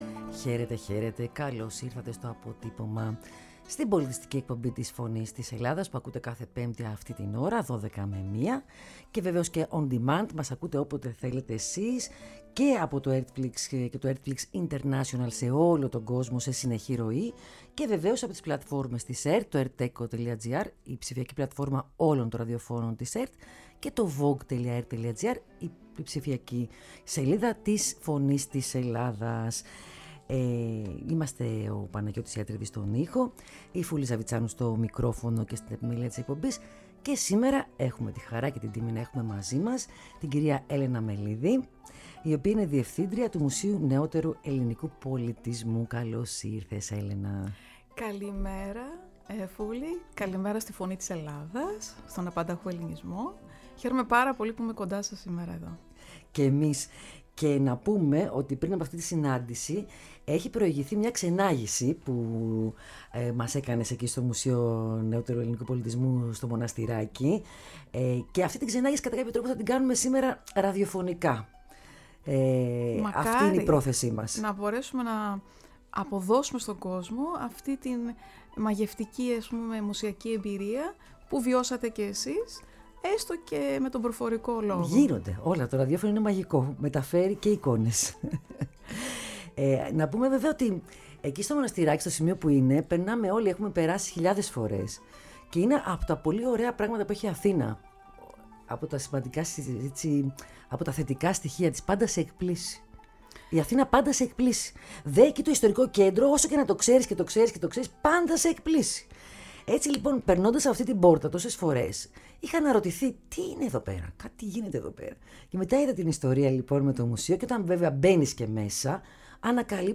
Η ΦΩΝΗ ΤΗΣ ΕΛΛΑΔΑΣ Αποτυπωμα Πολιτισμός ΣΥΝΕΝΤΕΥΞΕΙΣ Συνεντεύξεις Μουσειο Νεοτερου Ελληνικου Πολιτισμου